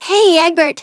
synthetic-wakewords
ovos-tts-plugin-deepponies_Rise Kujikawa_en.wav